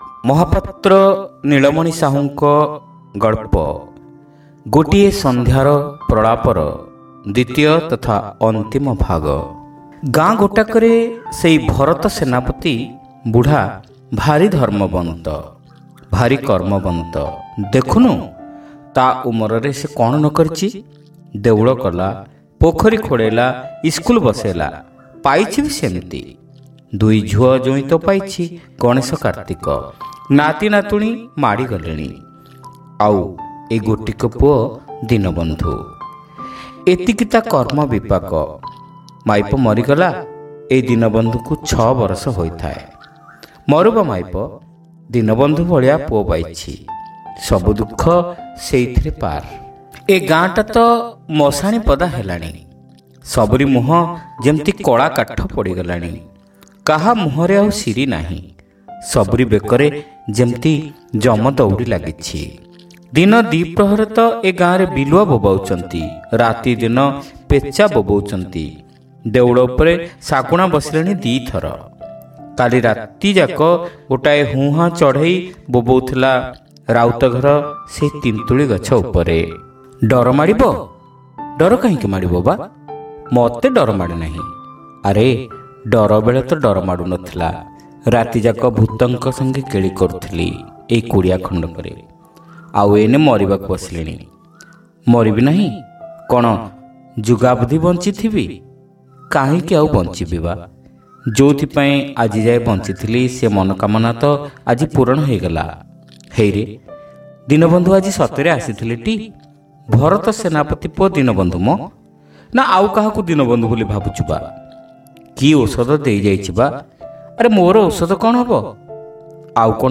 Audio Story : Gotie Sandhyara Pralapa (Part-2)